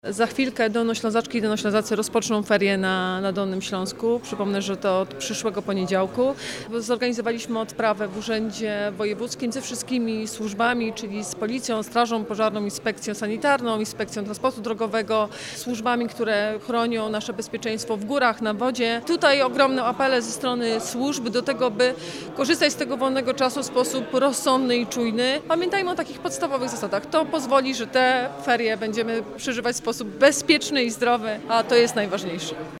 – Jesteśmy po odprawie sprawdzającej stan przygotowań każdej służby oraz instytucji do tego czasu. Bezpieczeństwo wypoczywających jest dla nas priorytetem – mówi Anna Żabska, Wojewoda Dolnośląska.